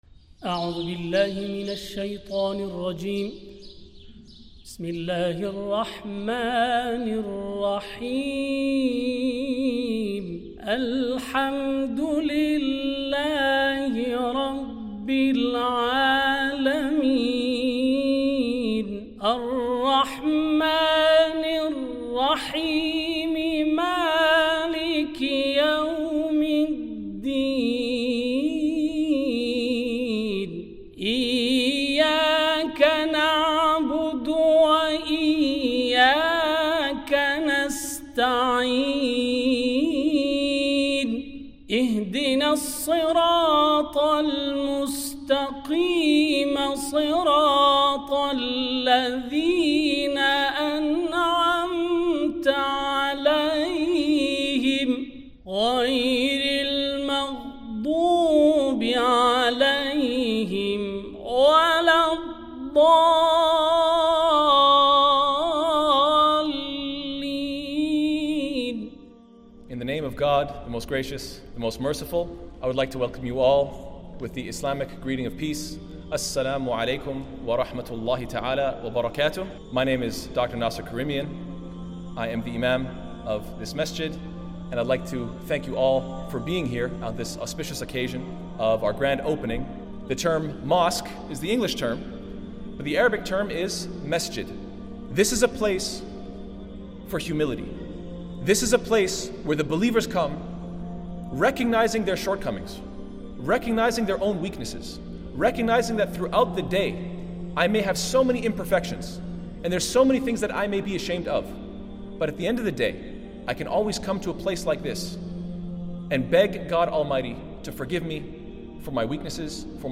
Alhuda Foundation Islamic Center of Indiana Opening Ceremony.mp3